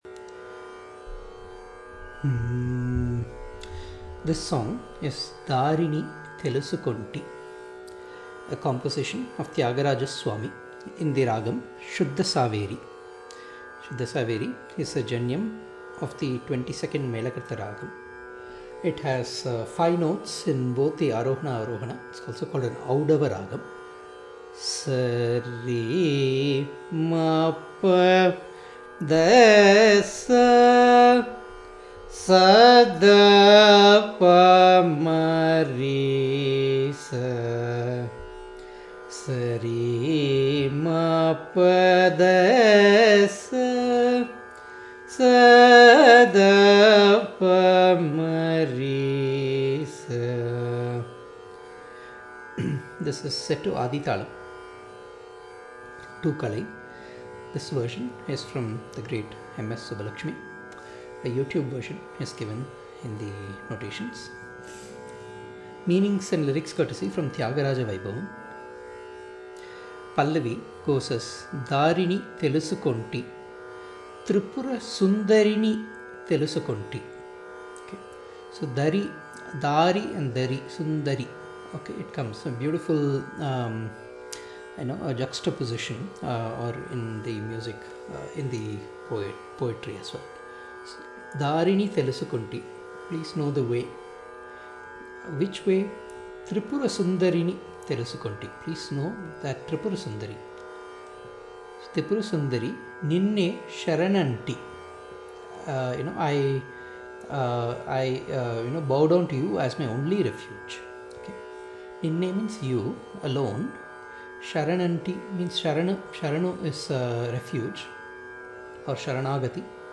dArini telusukoNTi (Class / Lesson) - Shuddha Saveri - Adi (2 kalai) - Tyagaraja - South Indian Classical (Carnatic) Music Archive: Classes / Lessons
Ragam: Shuddha Saveri {22nd Melakartha Janyam}
Talam: Adi (2 kalai)
darinitelusukonti-class.mp3